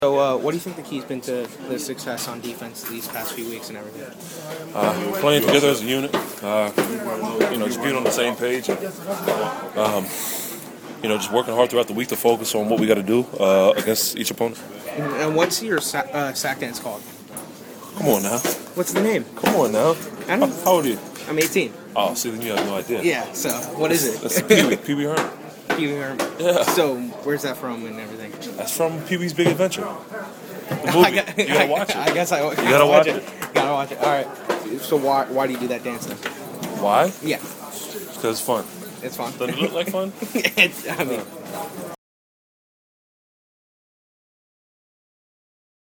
The last person I talked to in the Dolphins’ Locker Room was Jared Odrick.
phins-jared-odrick-locker-room.mp3